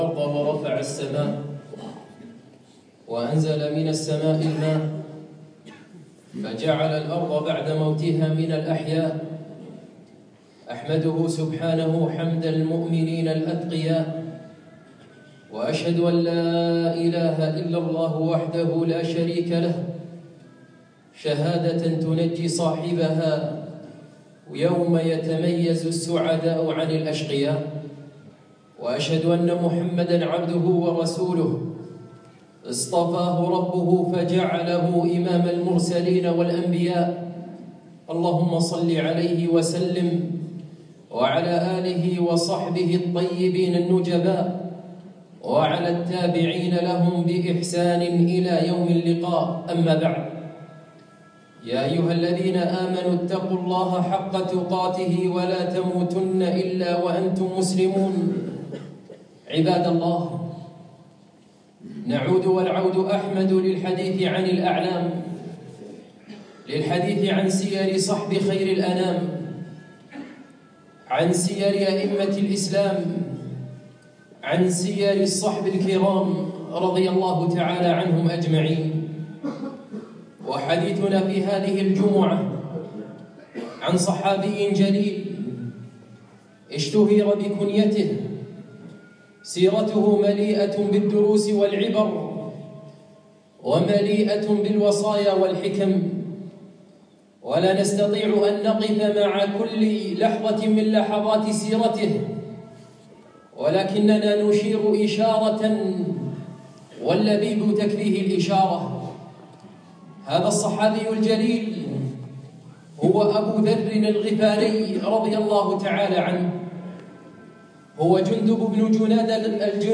يوم الجمعة 6 جمادى أول 1438 الموافق 3 2 2017 في مسجد العلاء بن عقبة الفردوس